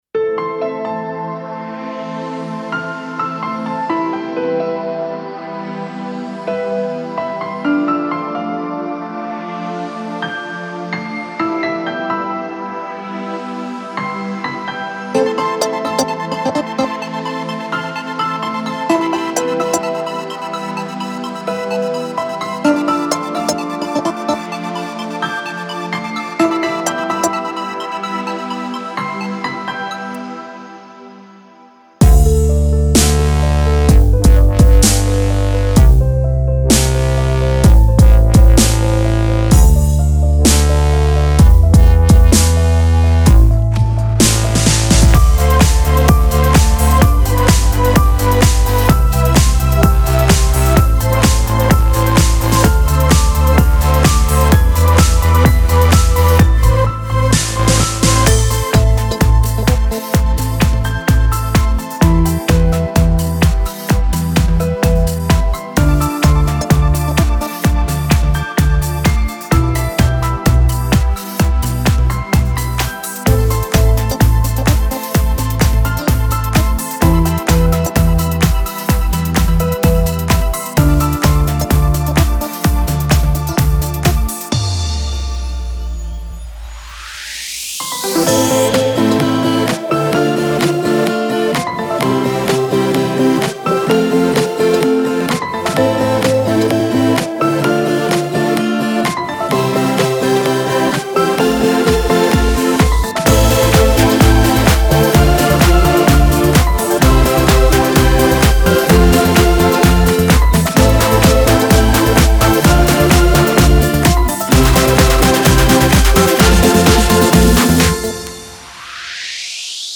a positive, rhythmic and motivating track
dance
electronic
instrumental
ambient
romantic
melodic
piano
progressive
dubstep